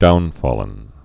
(dounlən)